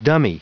Prononciation du mot dummy en anglais (fichier audio)
Prononciation du mot : dummy